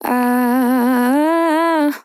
Categories: Vocals Tags: AhhhAh, dry, english, female, fill, LOFI VIBES, sample